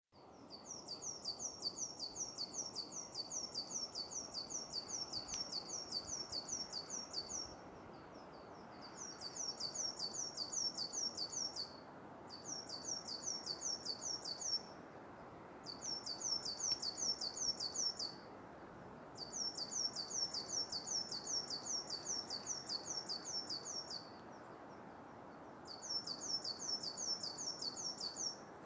Meža zīlīte, Periparus ater
Administratīvā teritorijaDaugavpils novads
StatussDzied ligzdošanai piemērotā biotopā (D)